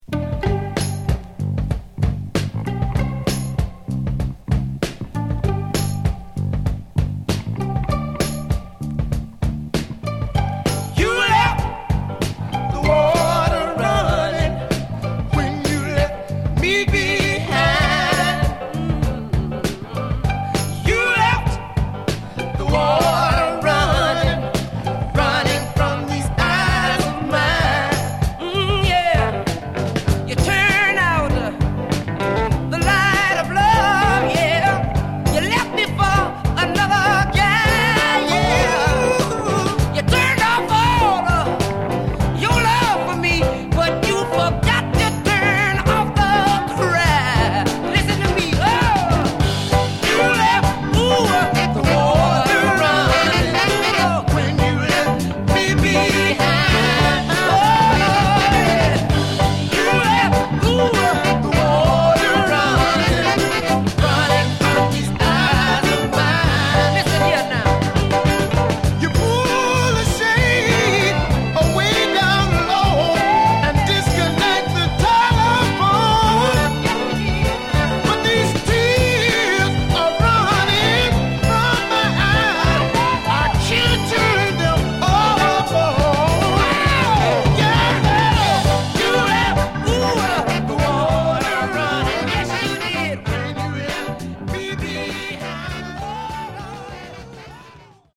A true soul gem.